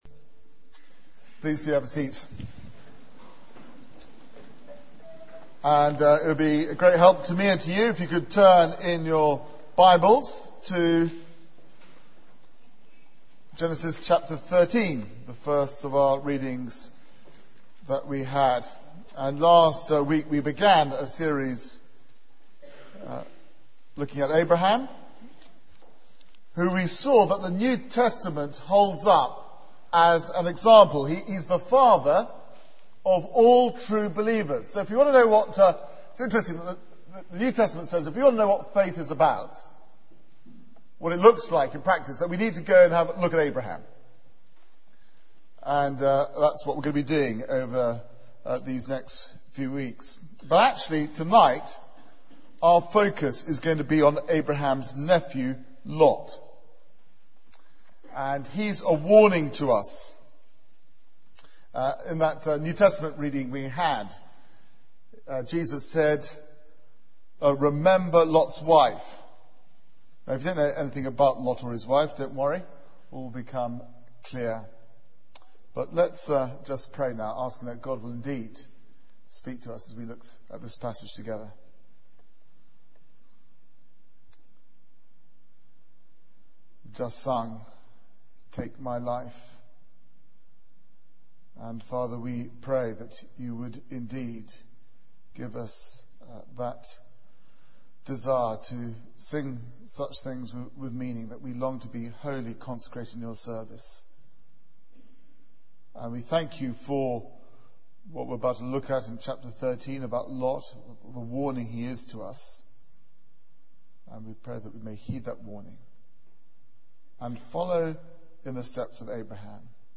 Media for 6:30pm Service on Sun 15th Nov 2009 18:30 Speaker: Passage: Genesis 13-14 Series: The Gospel According To Abraham Theme: The choice Sermon Search the media library There are recordings here going back several years.